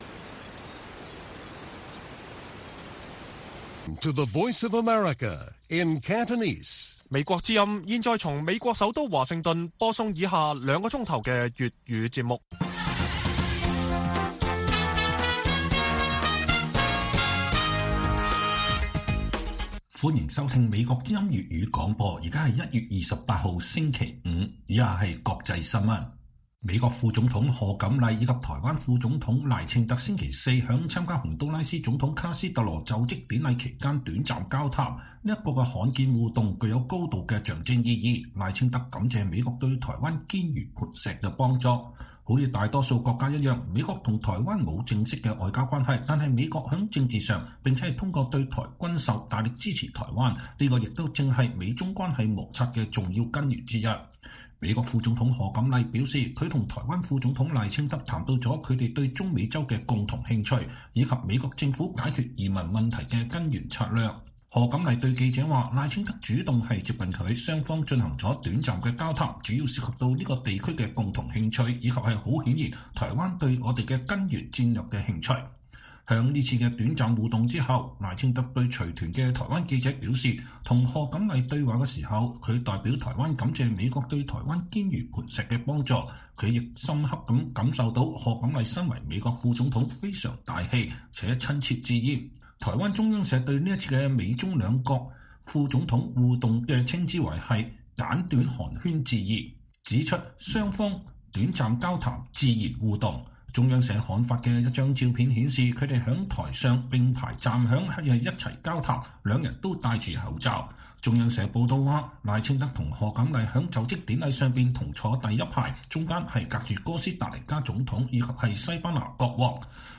粵語新聞 晚上9-10點: 美台副總統罕見互動台感謝美堅如磐石的幫助